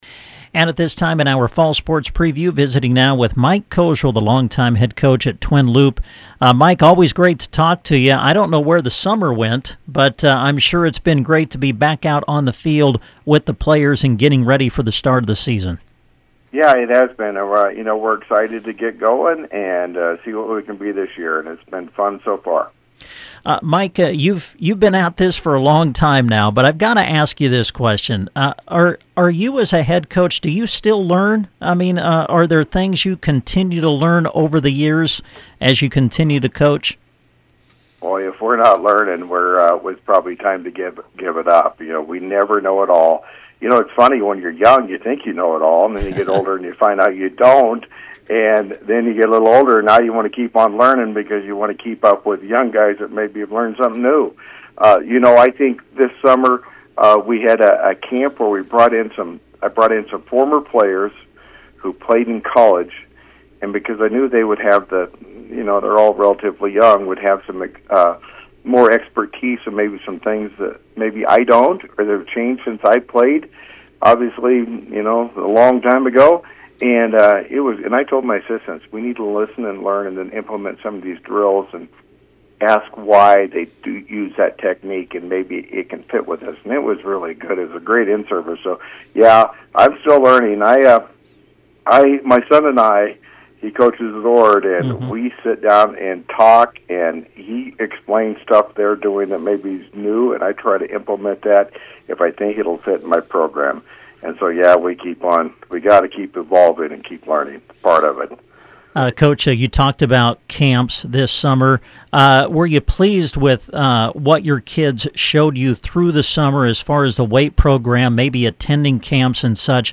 Twin Loup Football Preview – Interview